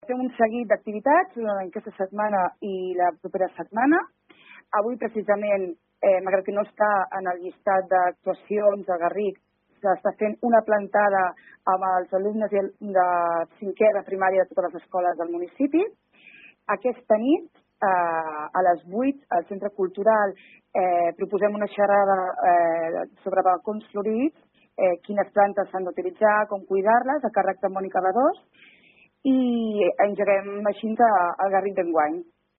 Ho explica Mireia Castellà, regidora de Medi Ambient de Malgrat de Mar.